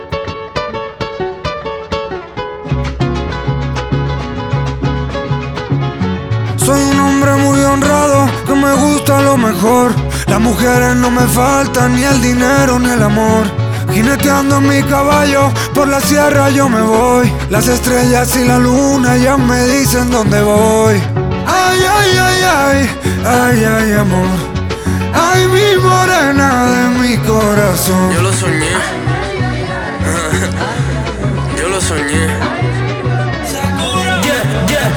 Жанр: Рэп и хип-хоп / Альтернатива